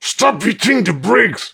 brickmove01.ogg